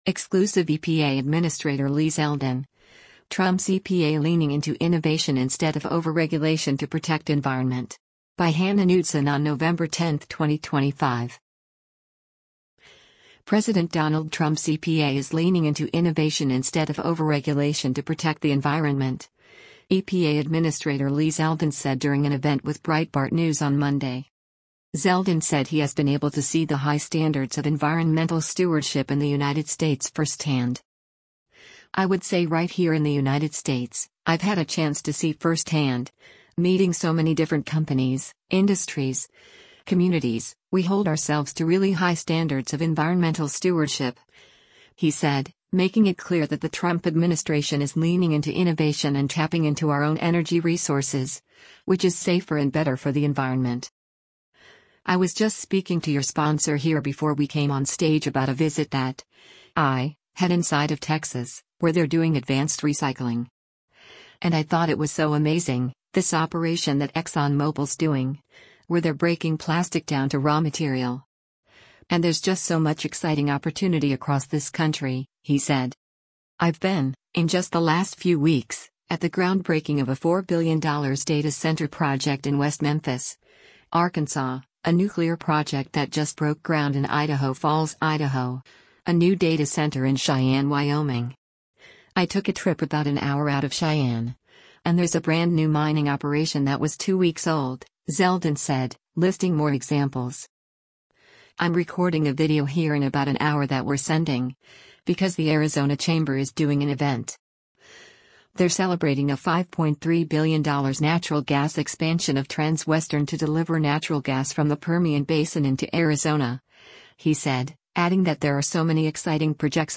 President Donald Trump’s EPA is “leaning into innovation” instead of overregulation to protect the environment, EPA Administrator Lee Zeldin said during an event with Breitbart News on Monday.